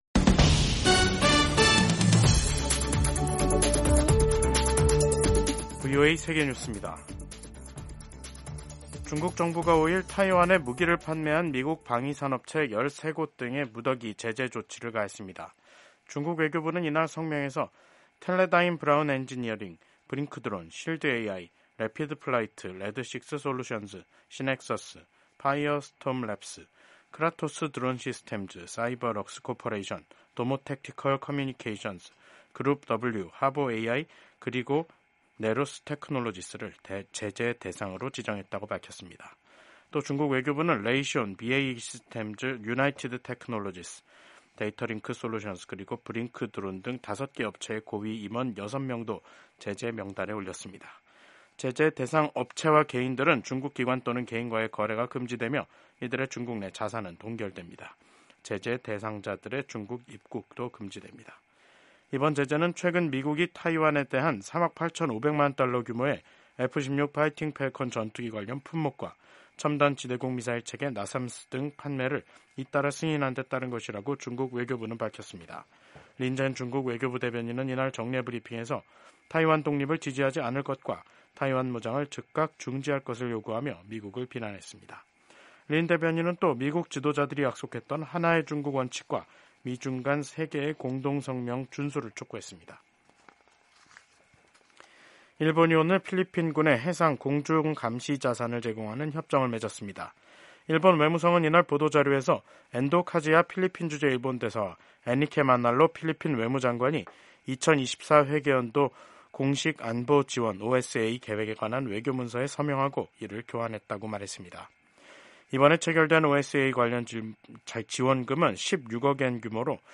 세계 뉴스와 함께 미국의 모든 것을 소개하는 '생방송 여기는 워싱턴입니다', 2024년 12월 5일 저녁 방송입니다. 프랑스에서 하원이 4일 미셸 바르니에 정부에 대한 불신임안을 통과시키면서 62년 만에 정부가 붕괴하는 일이 발생했습니다. 마이크 존슨 미 연방 하원의장이 취임 후 첫 해외 순방 중인 라이칭더 타이완 총통과 통화하고 타이완에 대한 미국의 확고한 지지를 재확인했습니다. 미국 연방 하원 선거 최종 결과, 공화당이 근소한 차이로 다수당이 됐습니다.